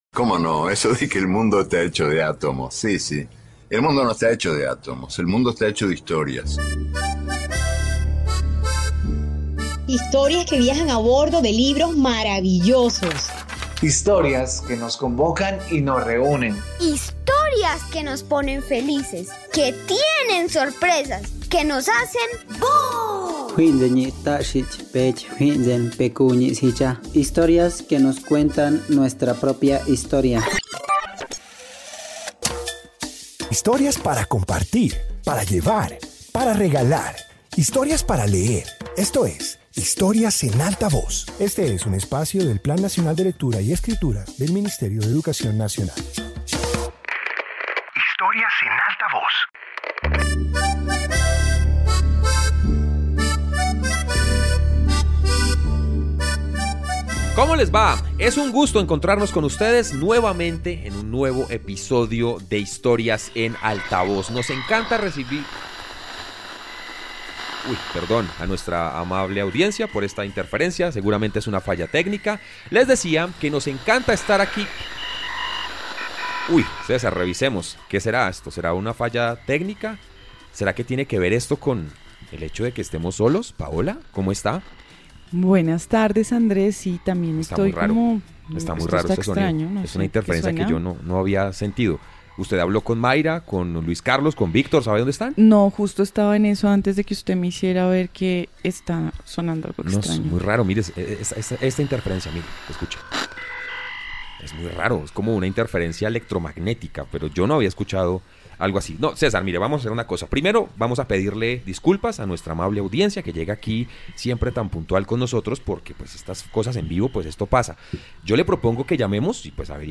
Introducción Este episodio de radio comparte interpretaciones sonoras inspiradas en el radioteatro. Presenta voces, efectos y ambientes que recrean escenas dramatizadas para escucharlas como si ocurrieran en un escenario.